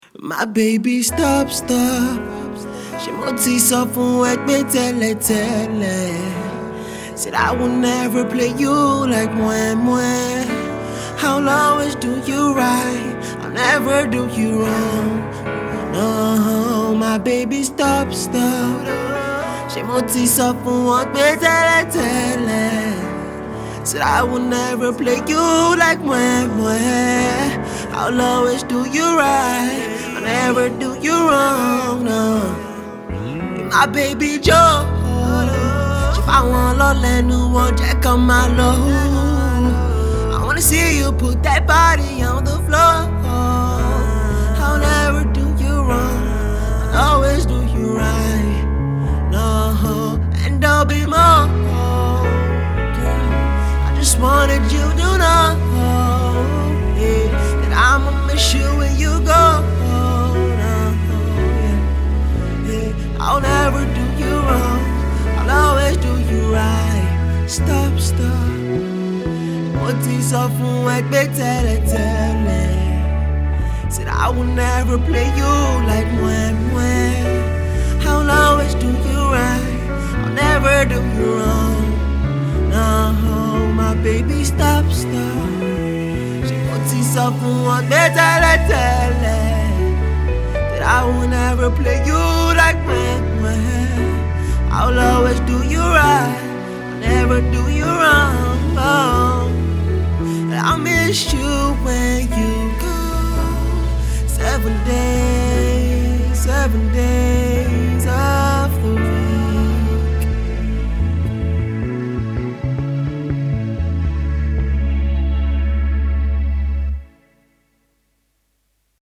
stellar acoustic cover